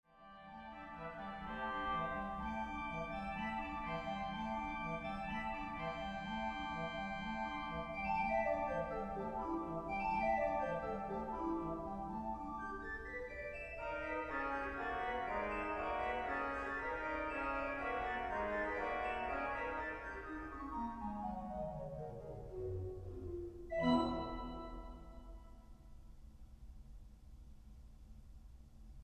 STYLE: Classical